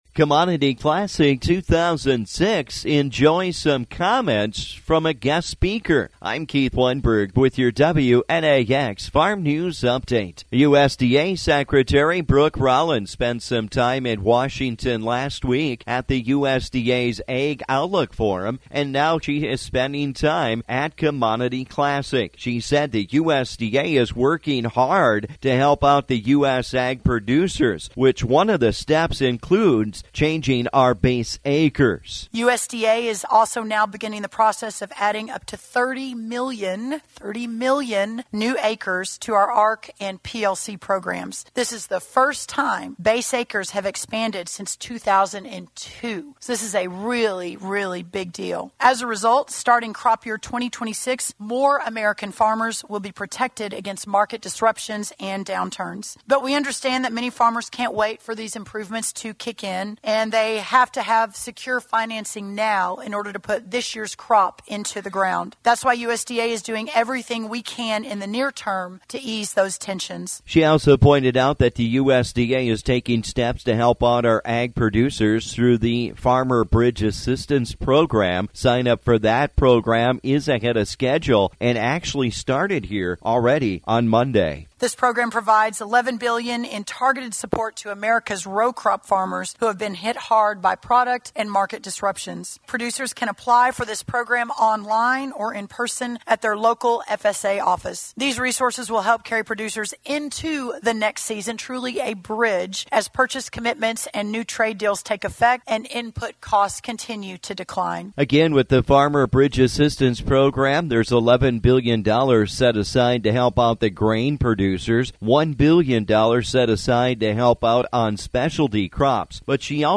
USDA Secretary speaks at Commodity Classic
USDA Secretary Brooke Rollins took some time yesterday to speak with those attending Commodity Classic.